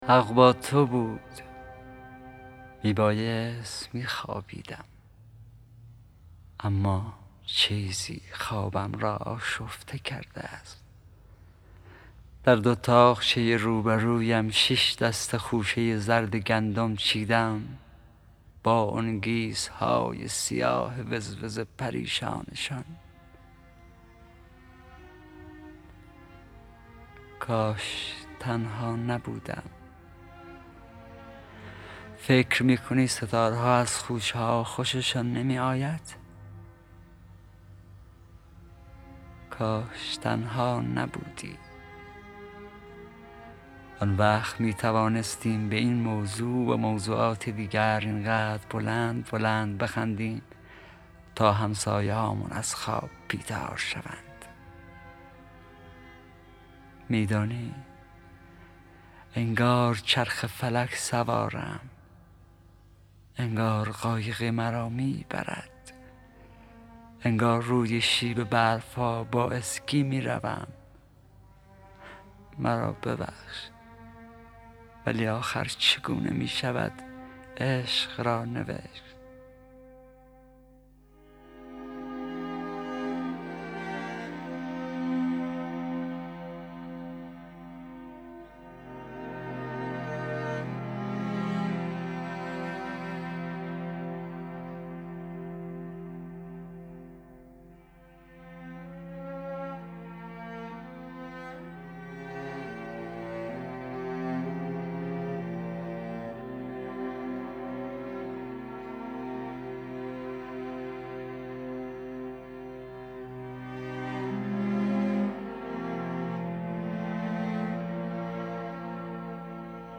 دانلود دکلمه حق با تو بود با صدای حسین پناهی
گوینده :   [حسین پناهی]
آهنگساز :   تورج شعبانخانی